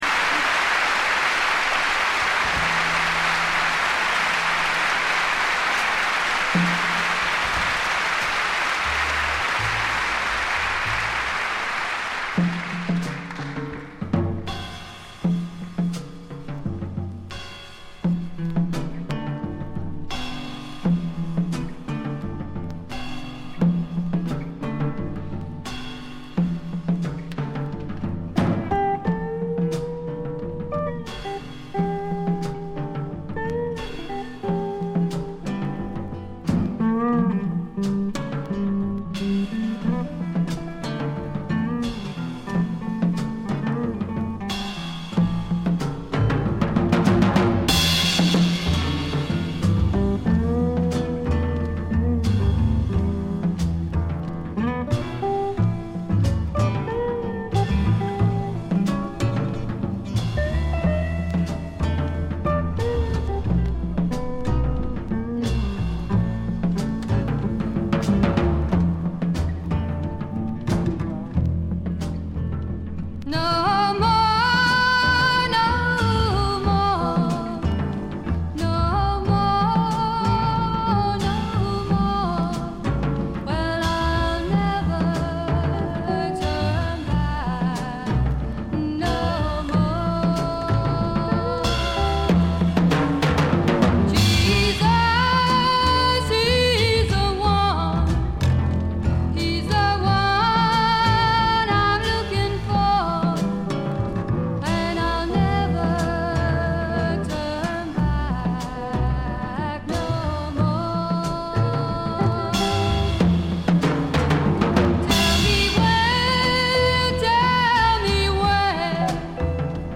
ホーム > レコード：英国 フォーク / トラッド
他はほとんどノイズ感無しで良好に鑑賞できます。
デビュー作発表後時を経ずしてリリースされた2枚組作品で、ライヴとスタジオがそれぞれ1枚づつ収録されています。
試聴曲は現品からの取り込み音源です。